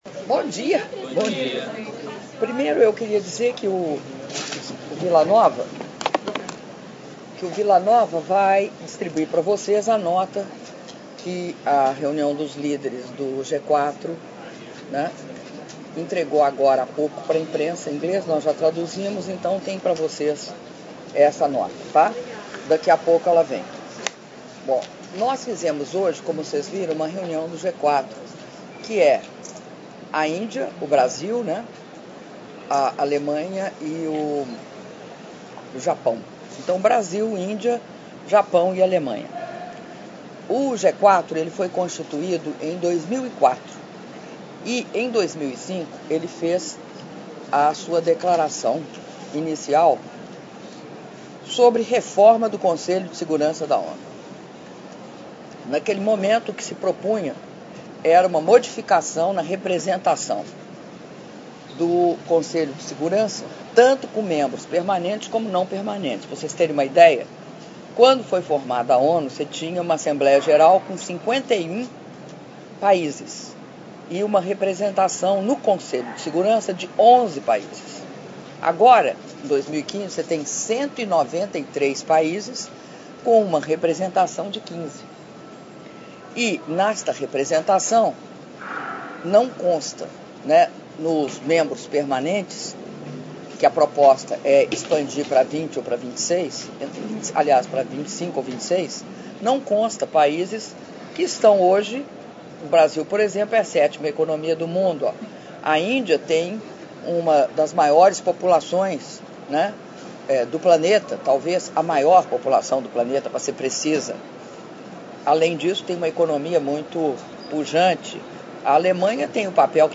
Áudio da entrevista coletiva concedida pela Presidenta da República, Dilma Rousseff, após Reunião de Cúpula do G4 - Nova Iorque/EUA (14min29s)